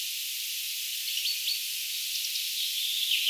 talitiainen?
tuollainen_ehkapa_talitiaisen_aantely.mp3